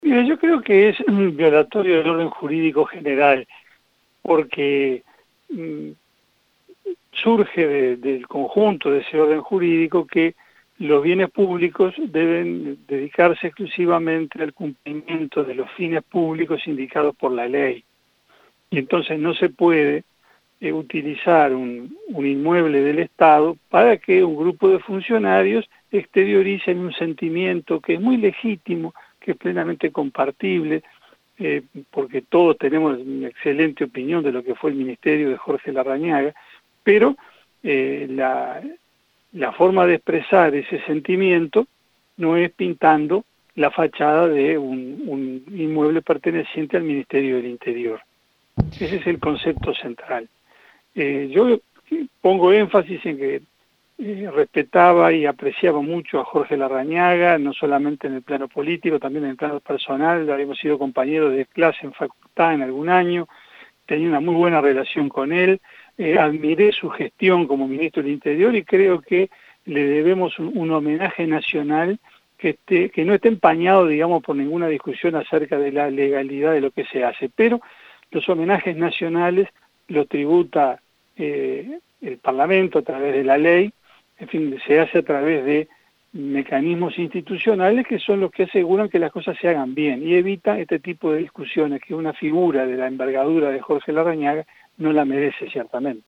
«No se puede utilizar un inmueble el Estado para que un grupo de funcionarios exteriorice un sentimiento, que es muy legítimo y plenamente compartible», expresó el legislador en diálogo con 970 Noticias.